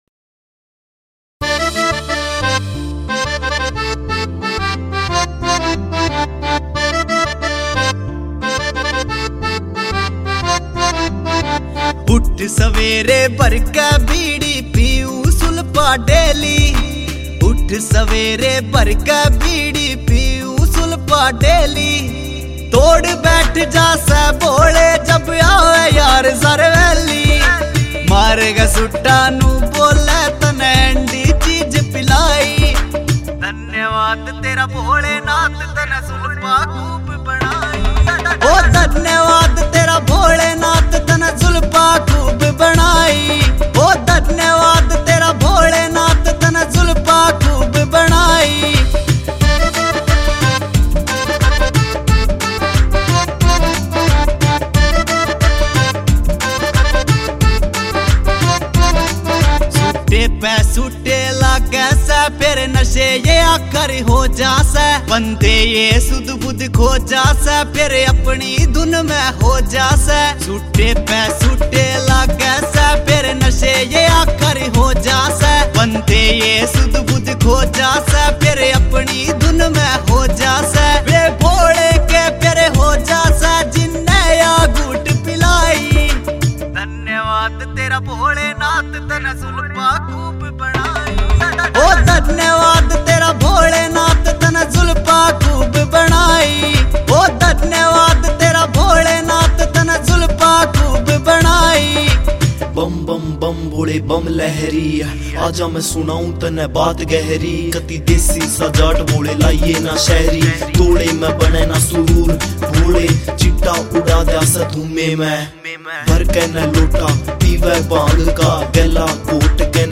Bhakti Songs